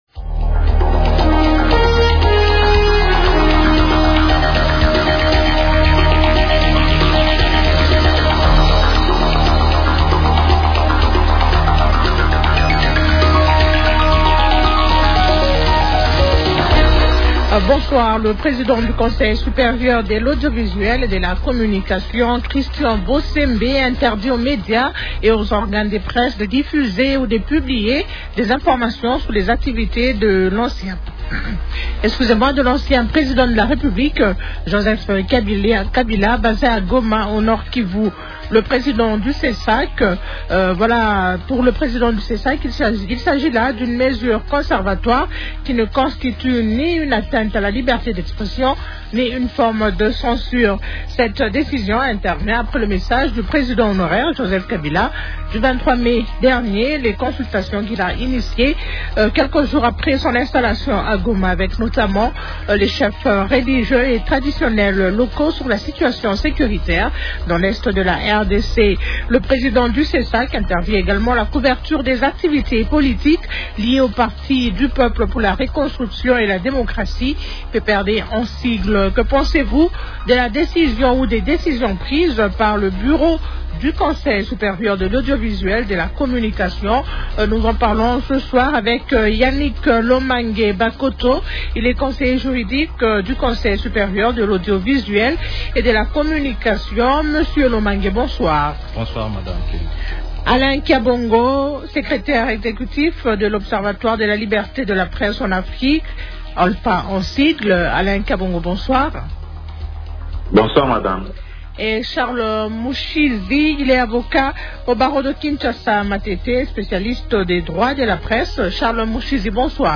L'actualité politique de ce soir